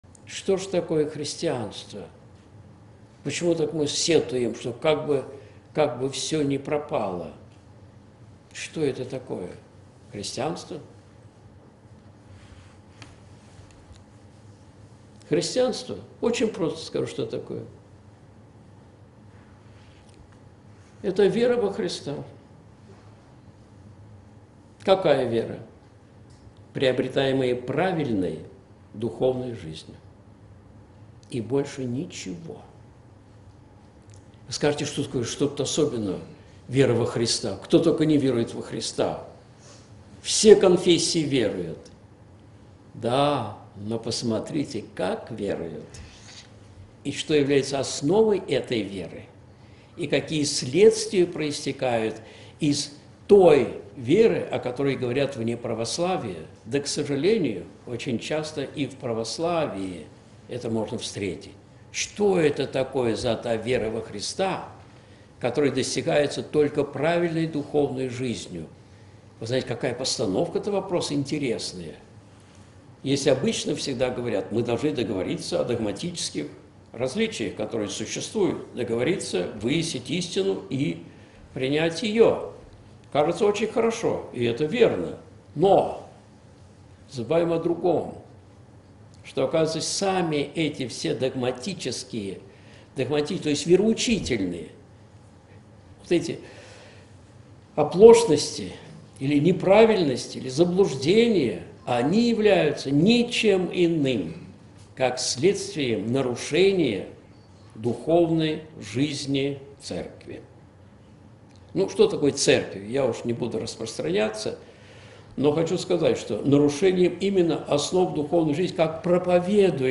Что такое христианство? С чего начинается вера во Христа Спасителя? (Москва. Храм Димитрия Донского, 21.12.2014)
Видеолекции протоиерея Алексея Осипова